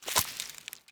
harvest_3.wav